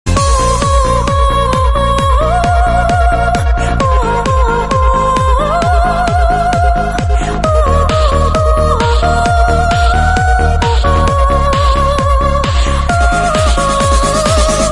без слов
оперный голос
Оперный голос под техно